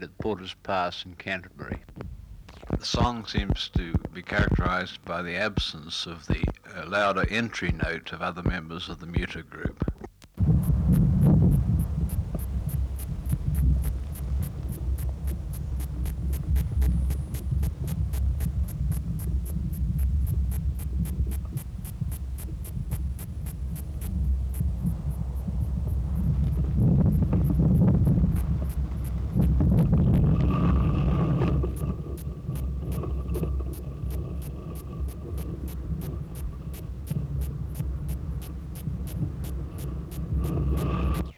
Kikihia angusta (79r13) | BioAcoustica
Reference Signal: 1 kHz at 12 dB at 1m at intervals
Recorder: Uher portable
13_Kikihia_angusta.wav